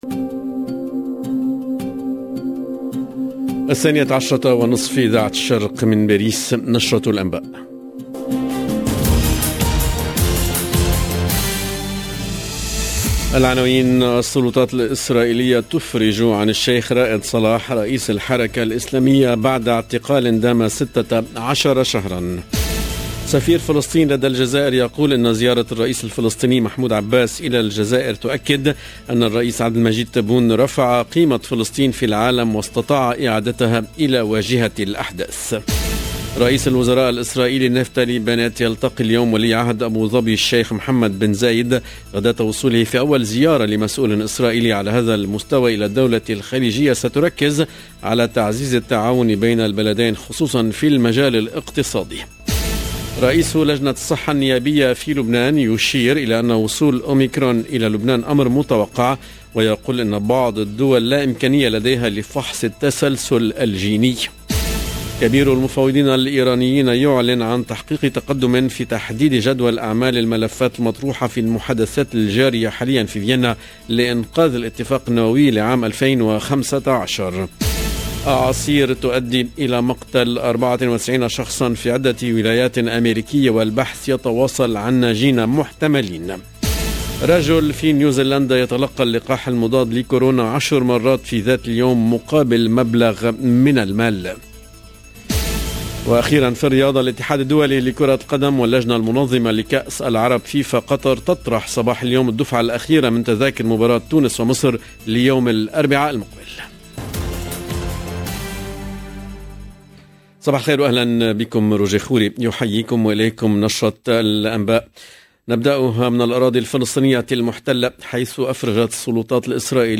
Journal en arabe